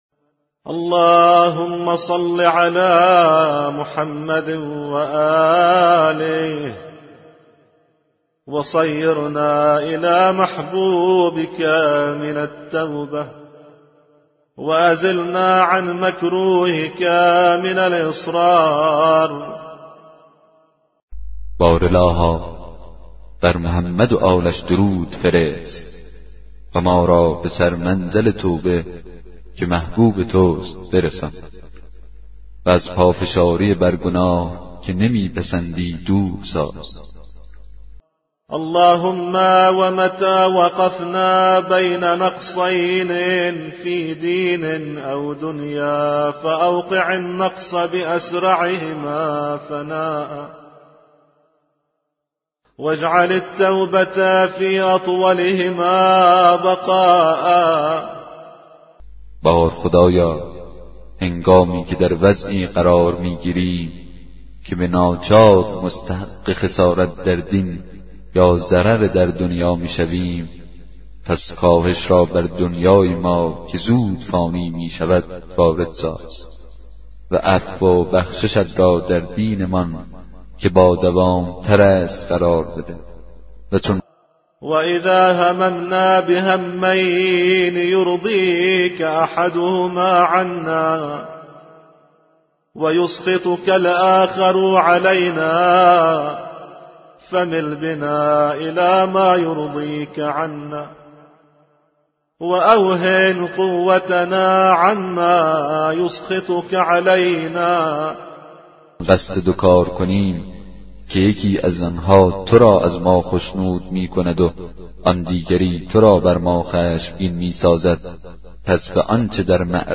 کتاب صوتی دعای 9 صحیفه سجادیه